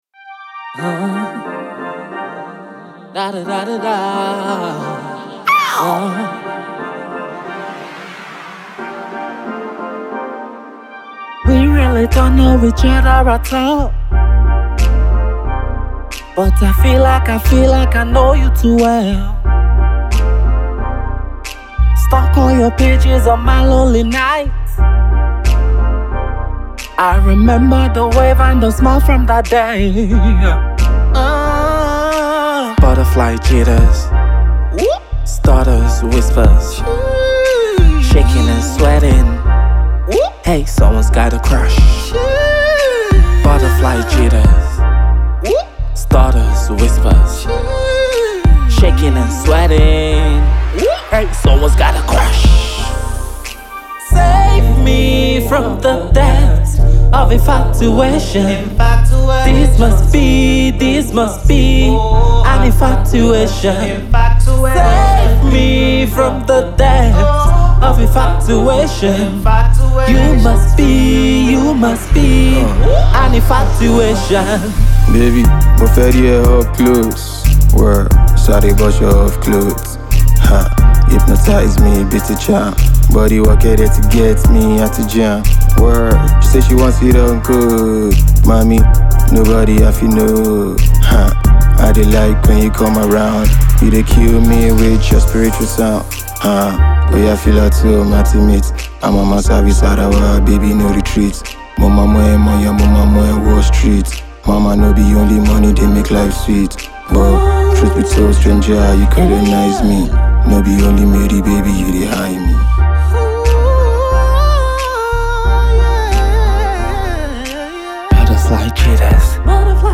with a pop sound while infusing hip-hop and trap